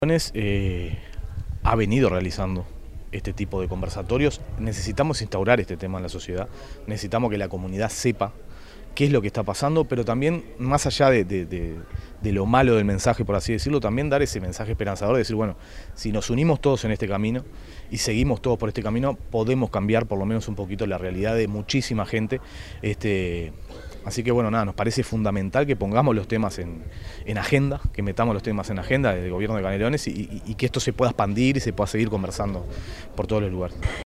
En marco del cierre de la Semana de la Convivencia, se realizó en Mercado Arenas de Carrasco (Municipio de Paso Carrasco) un conversatorio sobre la convivencia y rehabilitación como desafíos de Estado.
Por su parte, el Director de Seguridad y Convivencia, Nicolás Guillenea, se refirió a los desafíos que Canelones presenta para transformar la realidad del sistema penitenciario, de la seguridad pública y, de la rehabilitación, dentro de las competencias que competen al gobierno departamental.
director_de_seguridad_y_convivencia_nicolas_guillenea.mp3